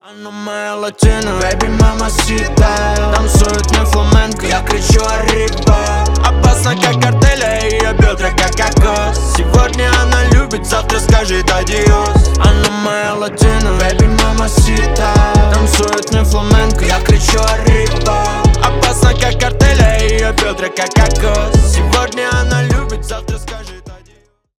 бесплатный рингтон в виде самого яркого фрагмента из песни
Поп Музыка # Танцевальные
весёлые